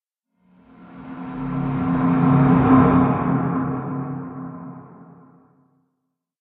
Minecraft Version Minecraft Version latest Latest Release | Latest Snapshot latest / assets / minecraft / sounds / ambient / cave / cave6.ogg Compare With Compare With Latest Release | Latest Snapshot
cave6.ogg